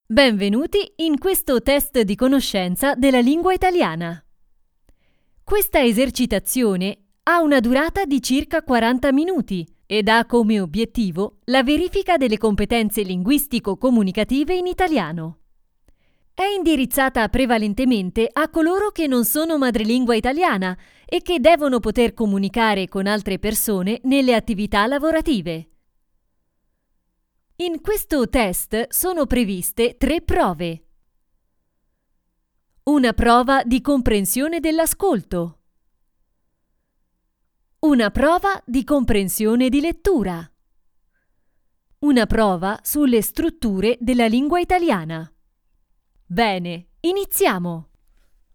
Clear voice, fresh, charming and persuading. Home studio recording service.
Sprechprobe: eLearning (Muttersprache):
E.learning_1.mp3